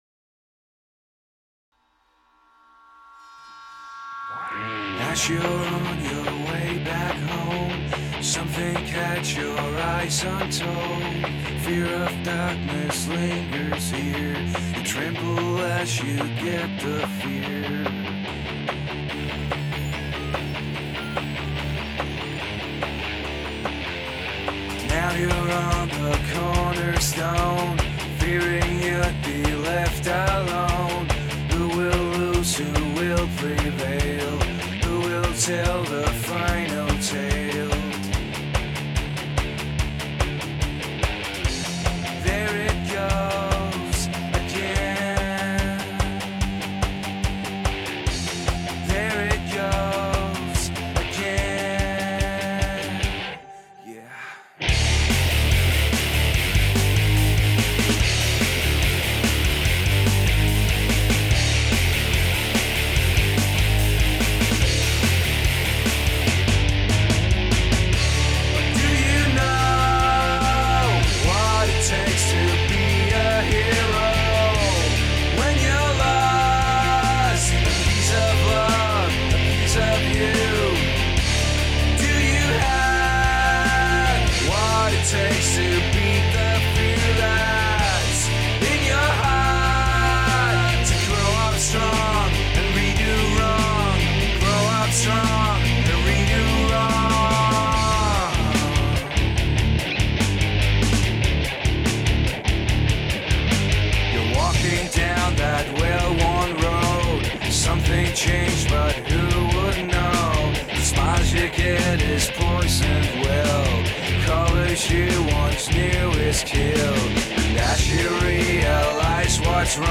Demo Version (MP3)